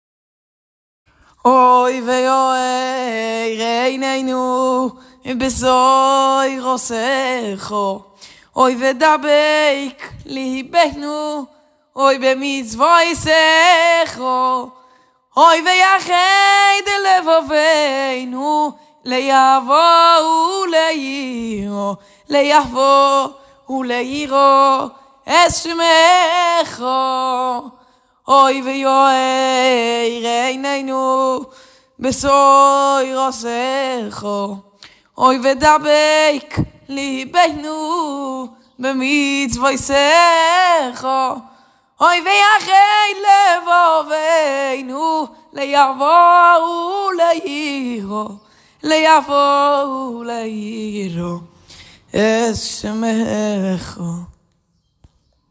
הלחנתי שיר והקלטתי את סקיצה קצרה לפזמון אשמח לשמוע את דעתכם. כמובן שאם אשלח לזמר זה יהיה עם ליווי וביצוע איכותי.